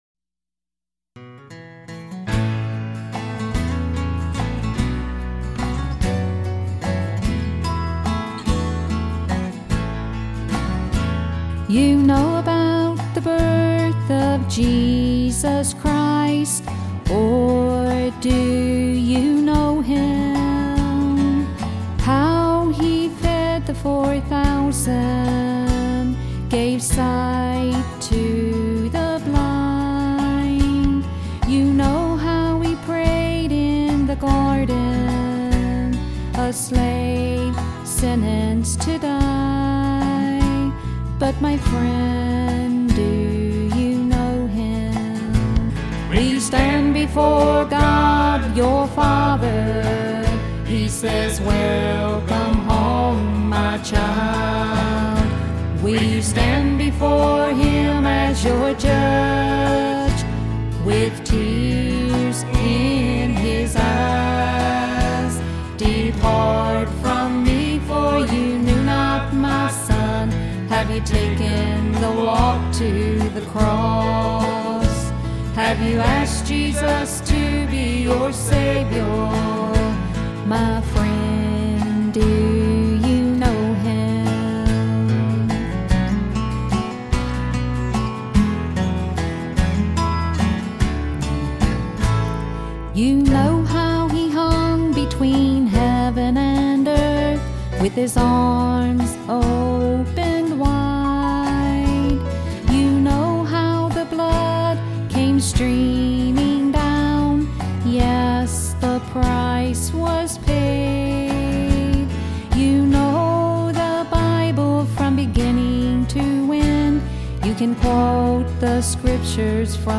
southern country and bluegrass songs